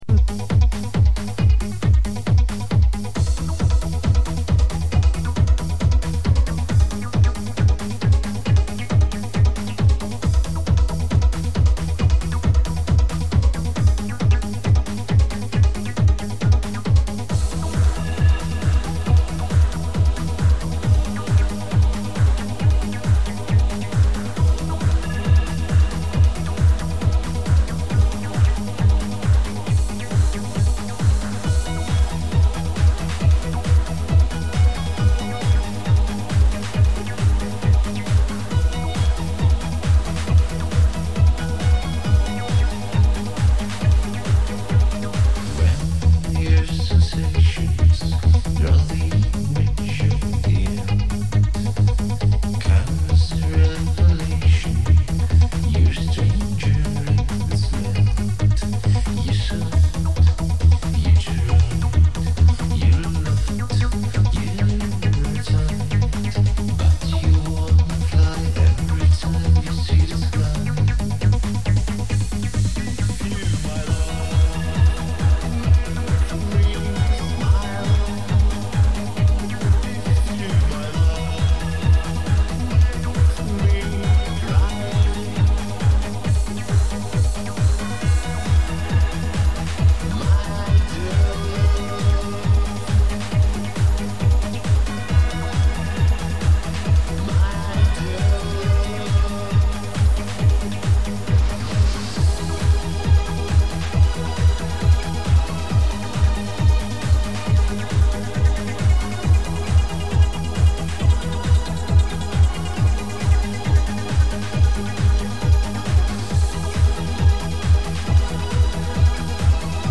НА ОПОЗНАНИЕ ЗАПИСЬ С РУССКОГО РАДИО